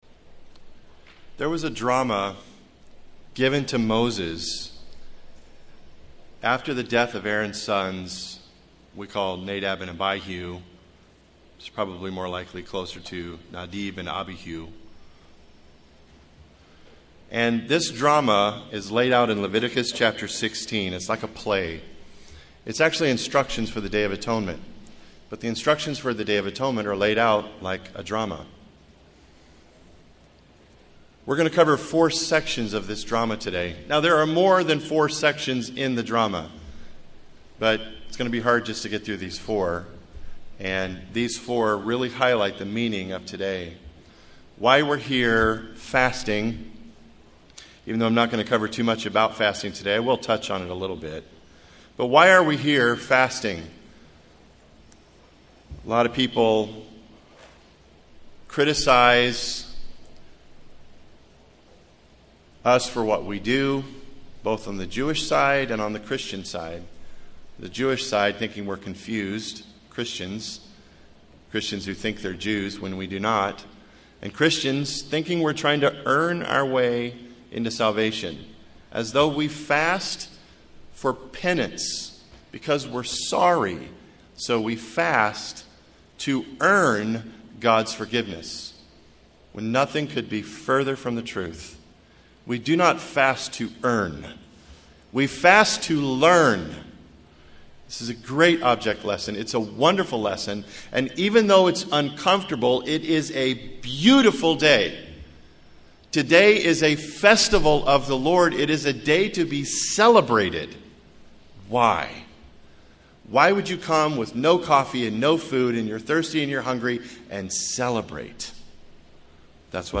In this sermon, we will look at sections of the drama that is played out in Leviticus chapter 16 that helps us understand the meaning of this Day of Atonement and what it represents in God’s plan for all mankind.